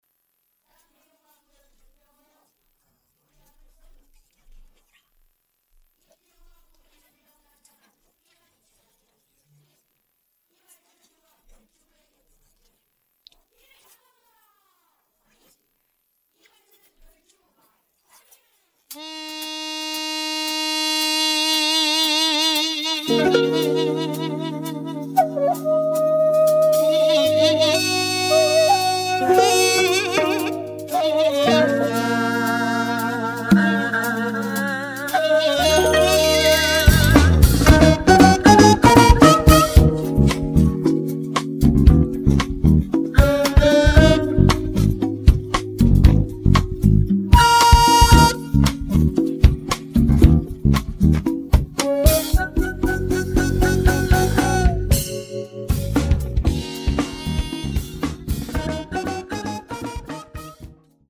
음정 원키 3:35
장르 가요 구분 Voice Cut